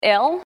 I know case is closed but I heard “ail.” Maybe it’s her Cali accent 😉
ail.mp3